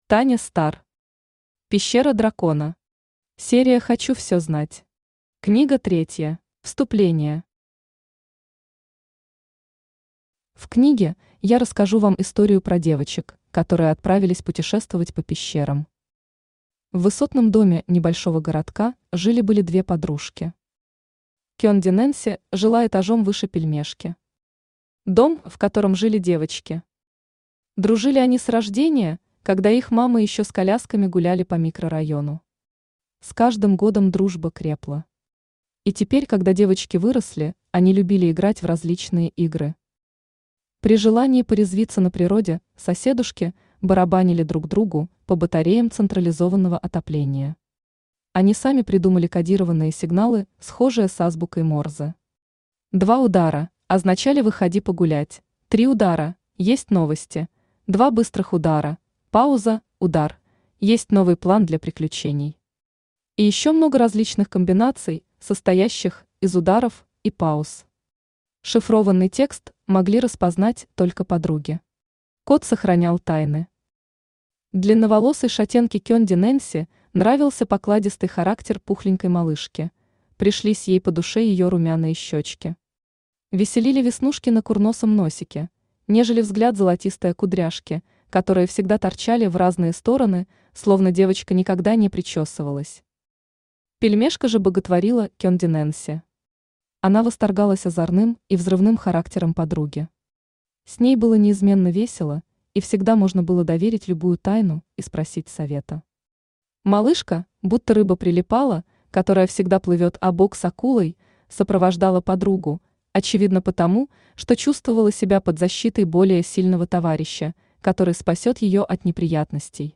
Аудиокнига Пещера Дракона. Серия «Хочу всё знать». Книга третья | Библиотека аудиокниг
Читает аудиокнигу Авточтец ЛитРес.